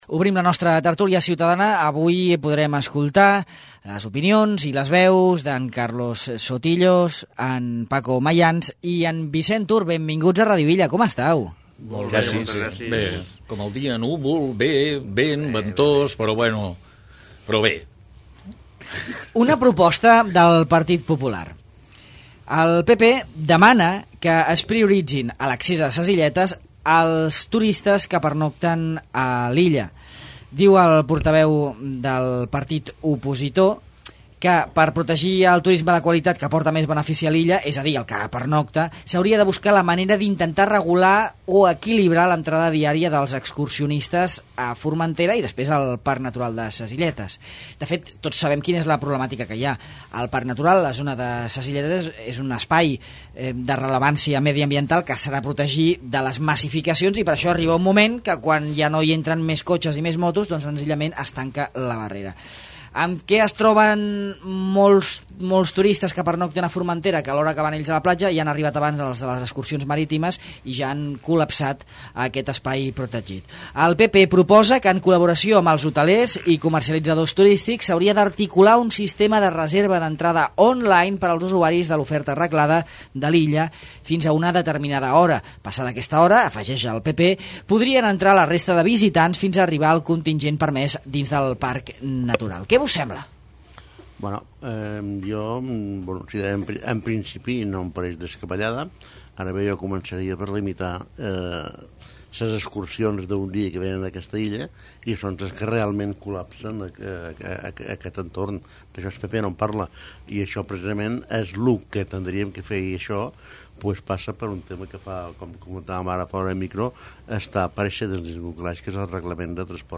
La nostra tertúlia ciutadana analitza les conseqüències que tendrà l’aplicació de la norma a Formentera